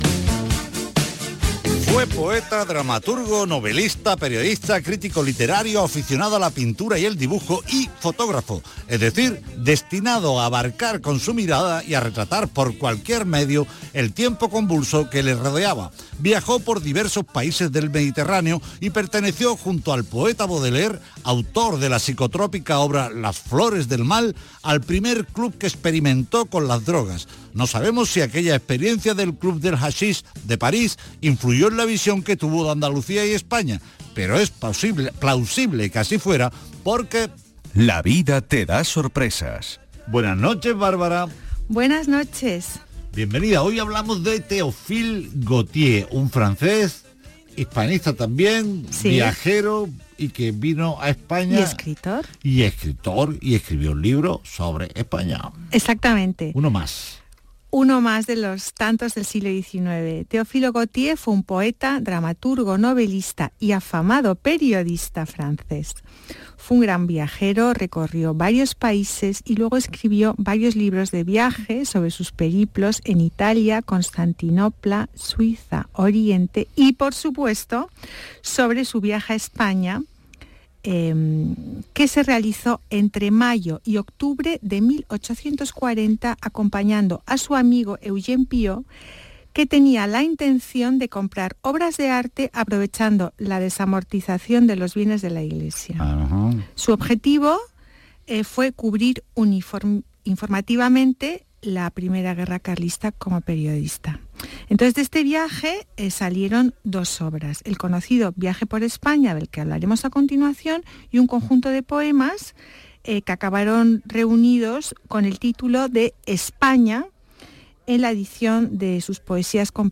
Aquí os dejo mi intervención en el programa de Radio Andalucía Información, «Patrimonio andaluz» del día 06/11/2022